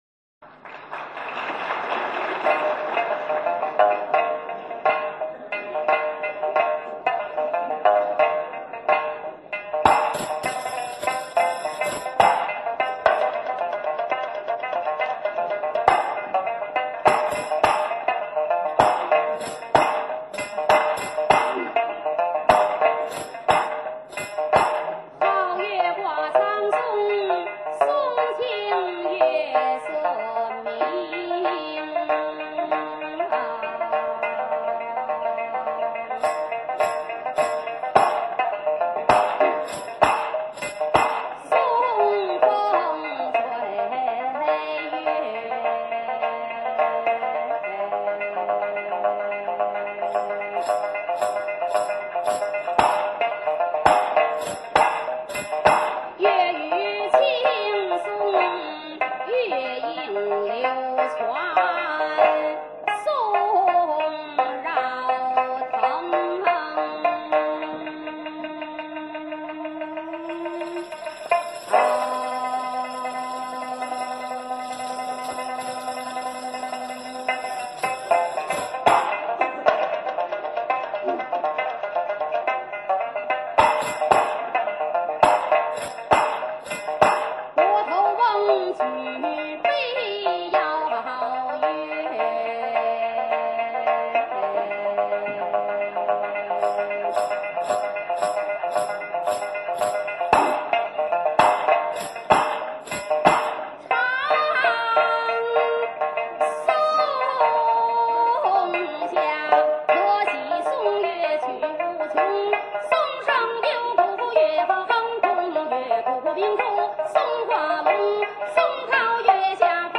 蘊生自民間土壤的說唱藝人
十八段原味酣暢的曲藝聲腔